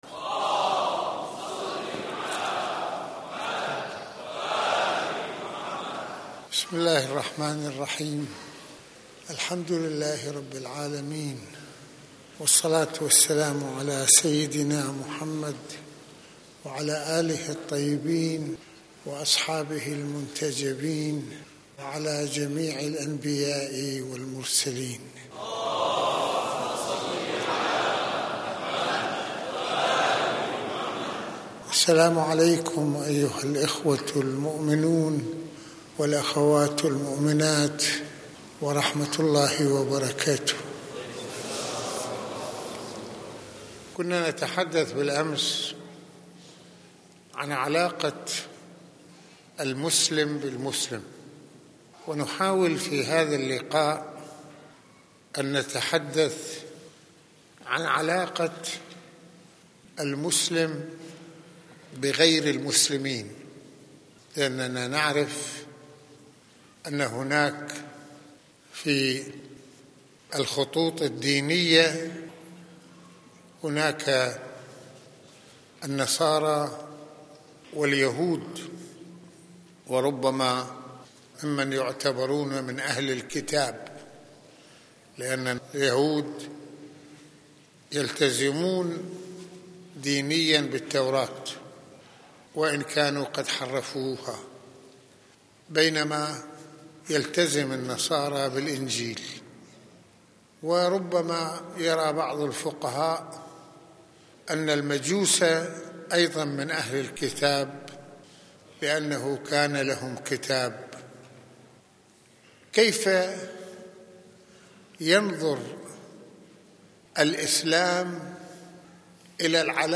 - المناسبة : عاشوراء المكان : مسجد الإمامين الحسنين (ع) المدة : 47د | 48ث المواضيع : علاقة المسلم بغير المسلمين - كيف ينظر الاسلام الى اليهود والنصارى ؟